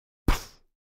Звук poof
• Категория: Исчезновение, пропадание
• Качество: Высокое